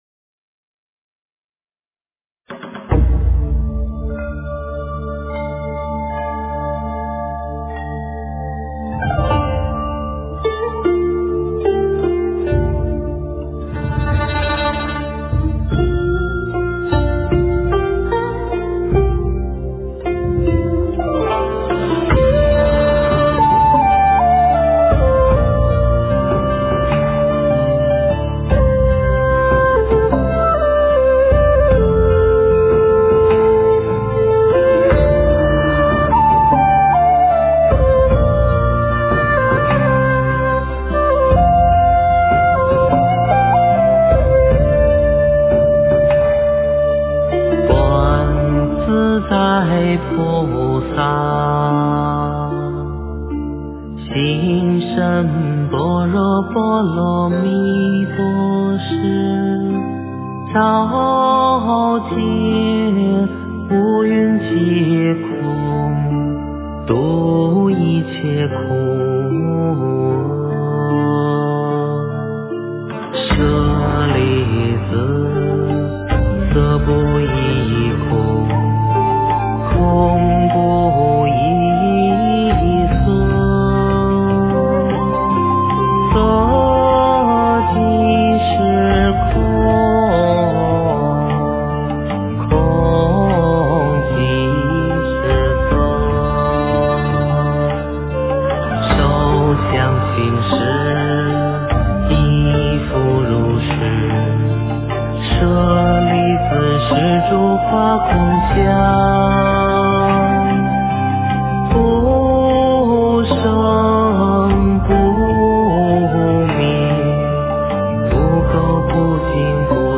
诵经
佛音 诵经 佛教音乐 返回列表 上一篇： 药师佛心咒 下一篇： 如意宝轮王陀罗尼 相关文章 八十八佛大忏悔文--佛光山梵呗乐团 八十八佛大忏悔文--佛光山梵呗乐团...